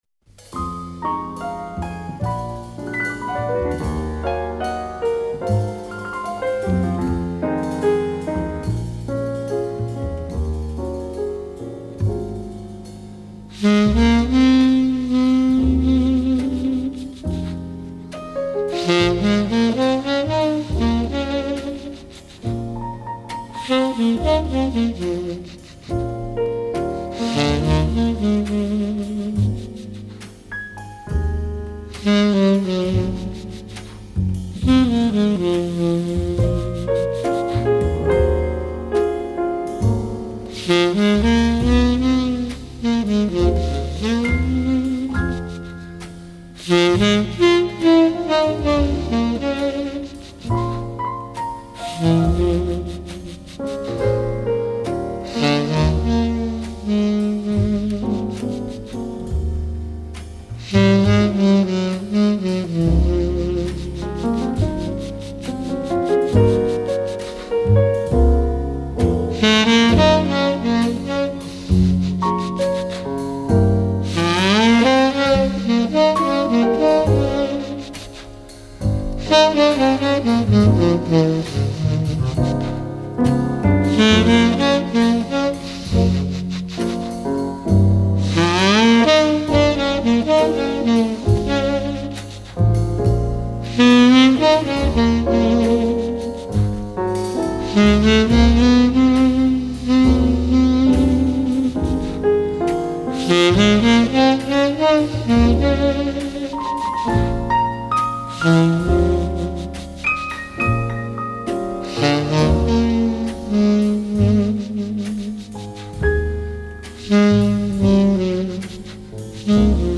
И немного для вечернего настроения джазовой музыки.